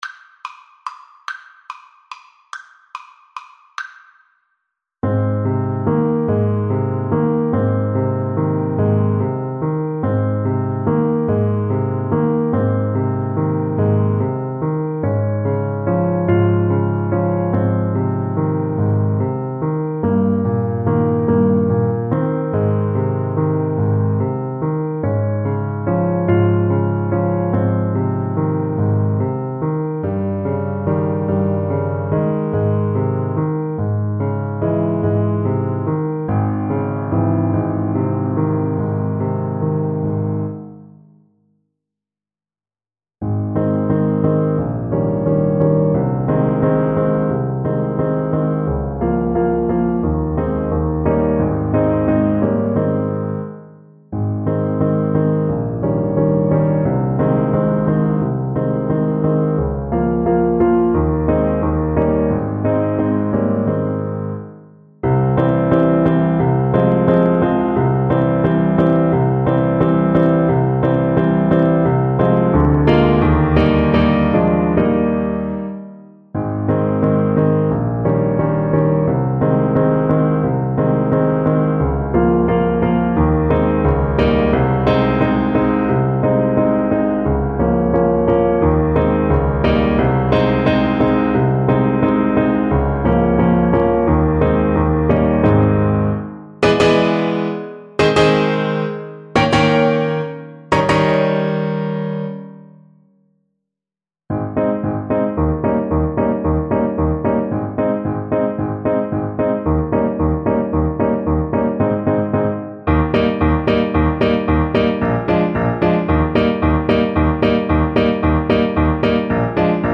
Alto Saxophone
3/4 (View more 3/4 Music)
Andante sostenuto (.=48)
Classical (View more Classical Saxophone Music)